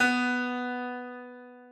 Harpsicord
b3.mp3